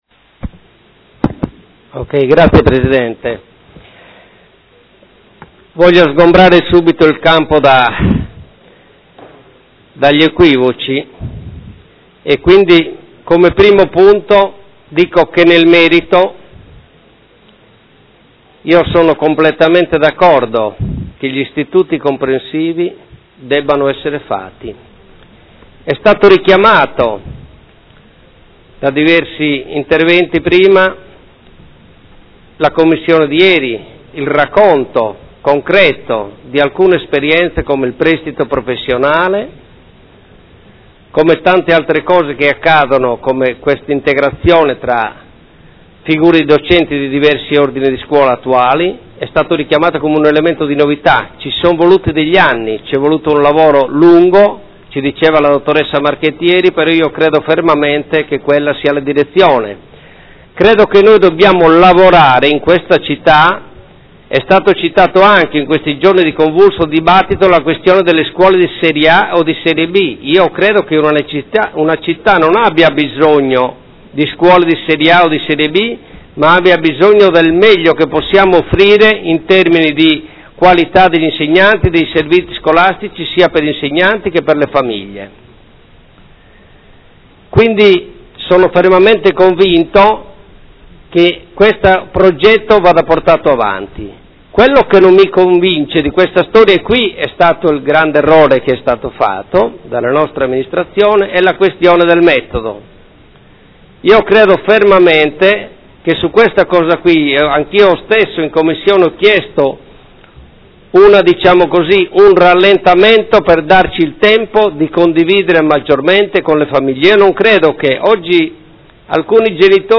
Seduta del 26/11/2015 Dibattito. Delibera: Riorganizzazione della rete scolastica e costituzione degli Istituti Comprensivi